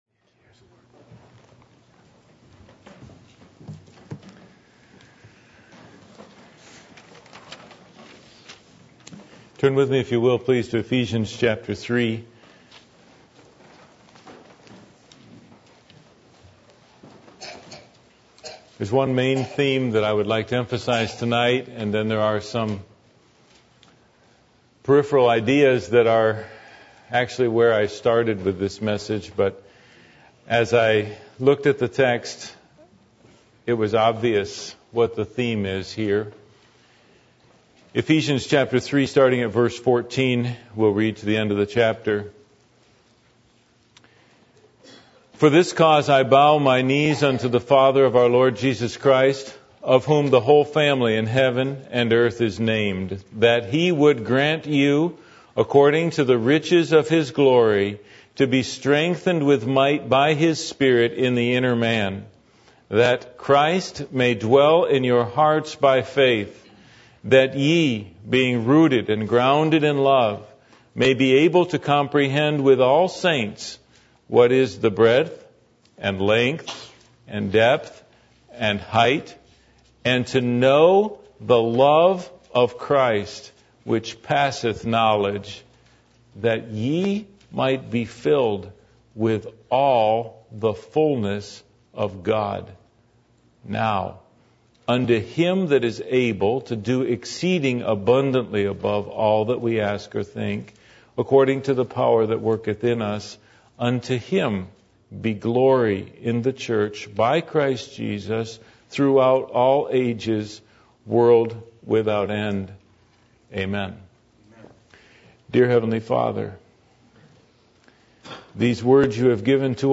Passage: Ephesians 3:14-21 Service Type: Sunday Evening %todo_render% « The Resurrection